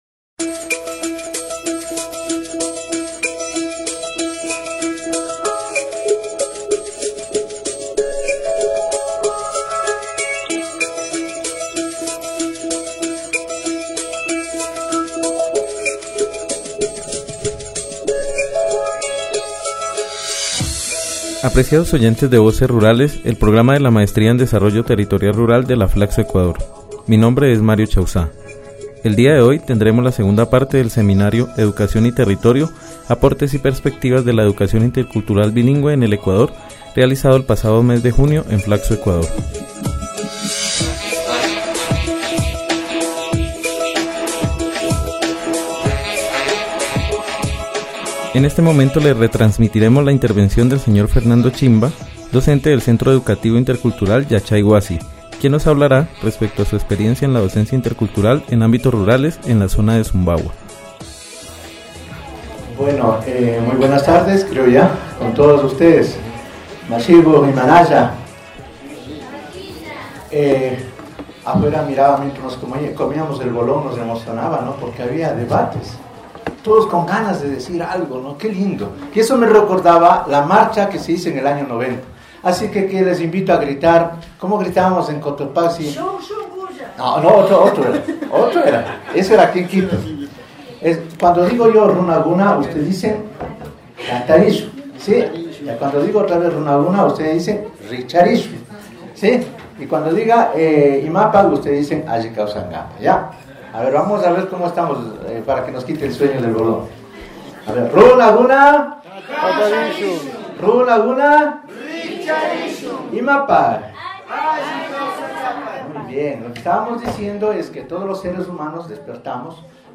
El pasado mes de junio de 2014 en las instalaciones de Flacso-Ecuador, se realizó el seminario educación y territorio: aportes y perspectivas de la educación intercultural bilingüe en el ecuador